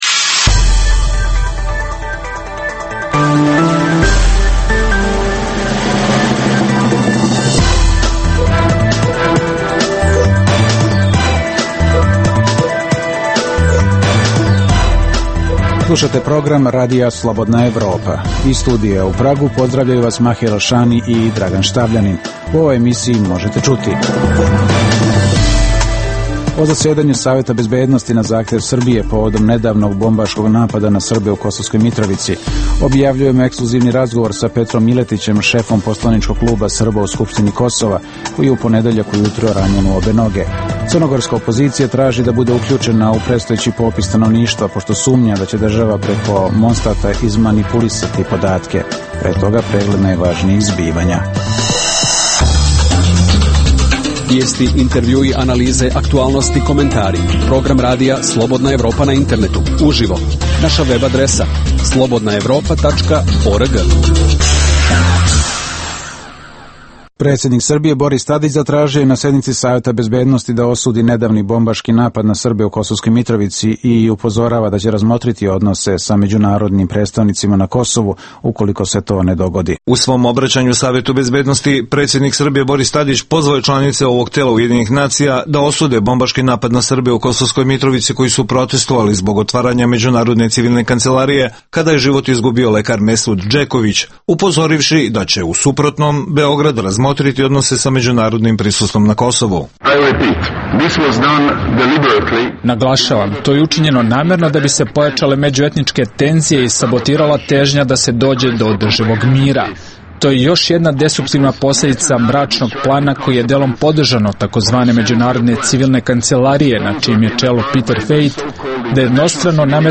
U ovoj emisiji možete čuti: O zasedanju Saveta bezbednosti na zahtev Srbije povodom nedavnog bombaškog napada na Srbe u Kosovskoj Mitrovici. Objavljujemo ekskluzivni razgovor sa Petrom Miletićem, šefom poslaničkog kluba Srba u Skupštini Kosova koji je u ponedeljak ujutro ranjen u obe noge. Crnogorska opozicija traži da bude uključena u predstojeći popis stanovništva pošto sumnja da će država preko Monstata izmanipulisati podatke.